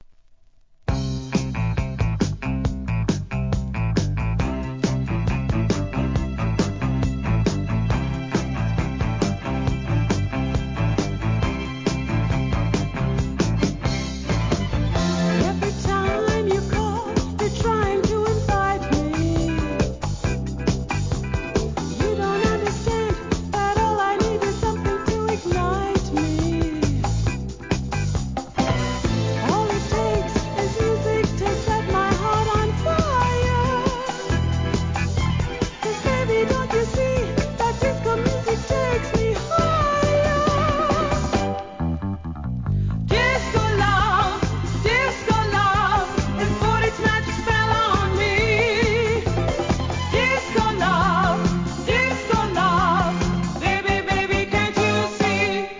¥ 550 税込 関連カテゴリ SOUL/FUNK/etc...
分かりやすいフックで乗れます!